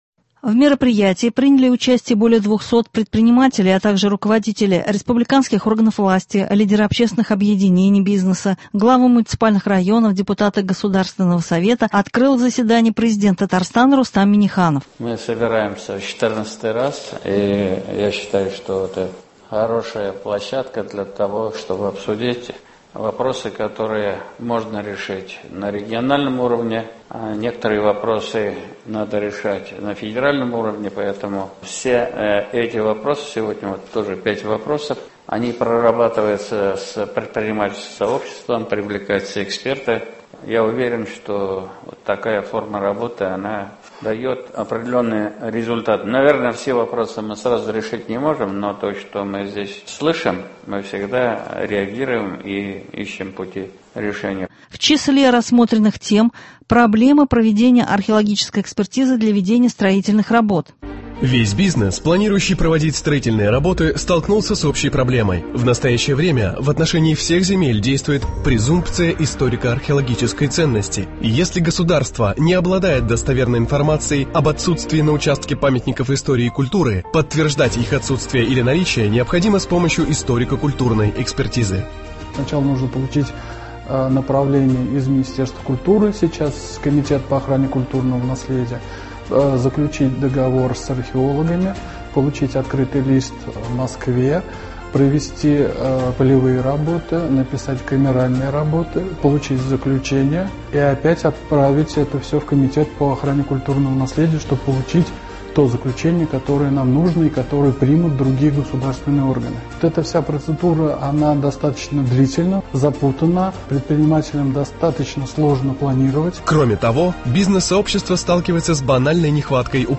Репортаж с очередного заседания Совета по предпринимательству, на котором бизнес — сообщество вместе с Президентом республики обсуждало проблемы, мешающие развитию малого и среднего бизнеса.